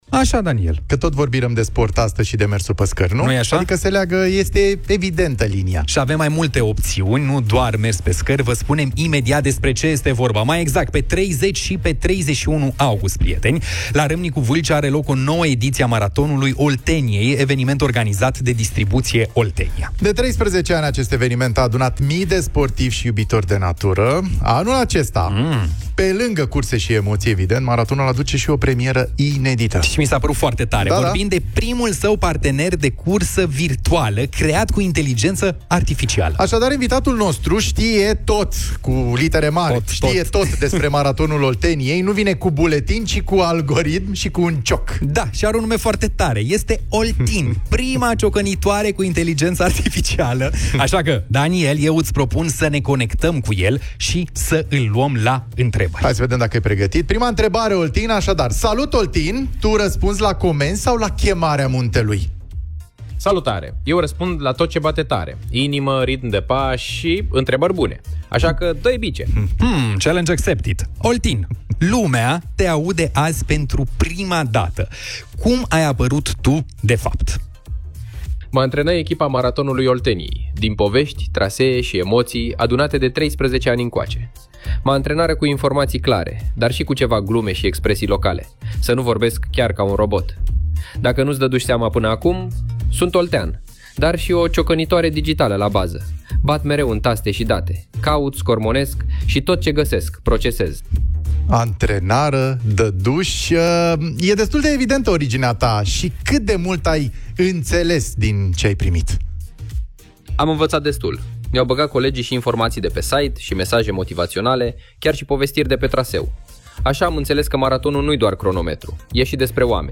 Partenerul digital de cursă al Maratonului Olteniei invitat în matinalul de vară